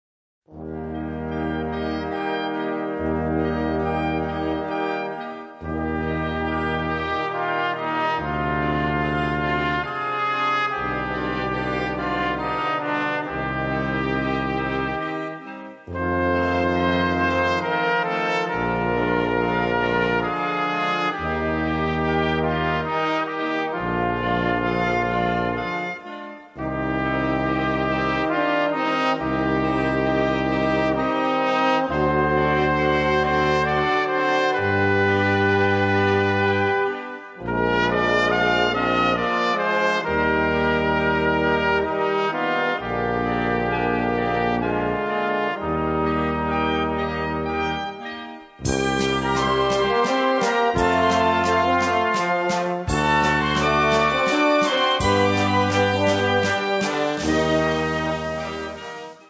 Gattung: Solo für Flügelhorn und Tenorhorn
Besetzung: Blasorchester